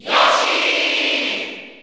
Category: Crowd cheers (SSBU) You cannot overwrite this file.
Yoshi_Cheer_Italian_SSB4_SSBU.ogg